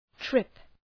trip Προφορά
{trıp}